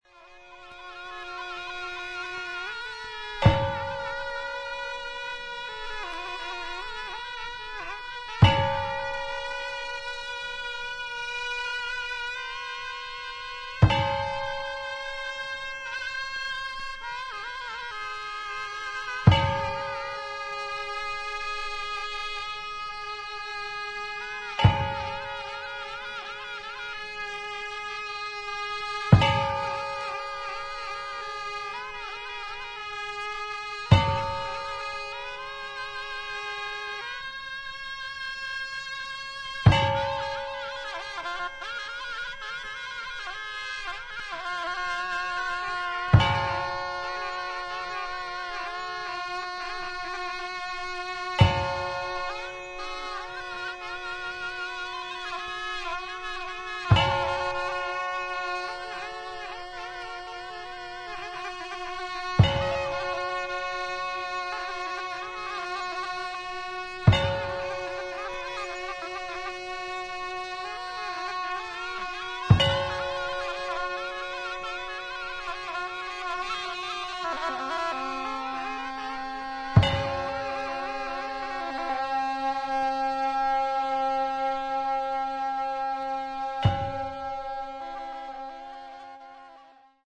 本作は、ブータンの伝統的な宗派「ドゥプカ派」の儀式に焦点を当て、総勢76人のラマ僧と僧侶が神聖な寺院で長いトランペット、ショーム、シンバル、太鼓などのチベット楽器を用いて詠唱し、演奏。臨場感あふれる声明や打楽器の演奏、メディテーティブな僧院音楽が生々しく記録された大変貴重な音源です。
B10 Processional Music For Shawms And Percussion